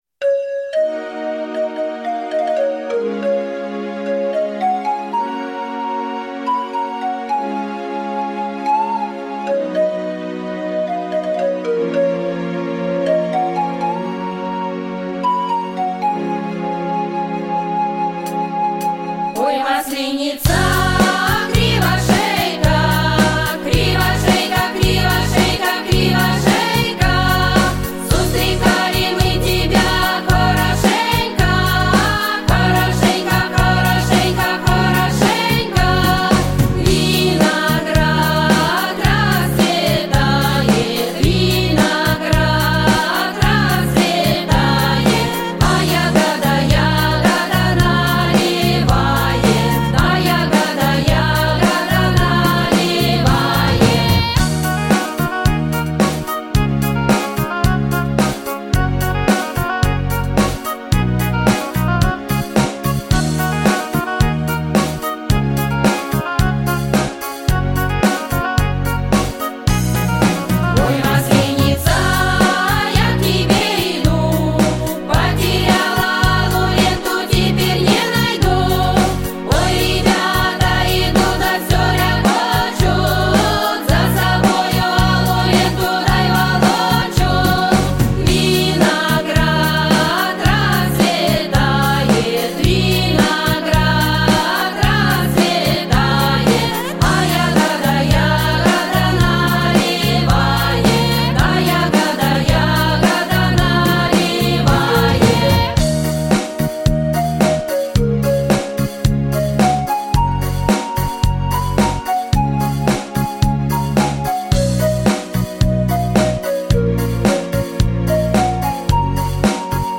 • Жанр: Детские песни
народный мотив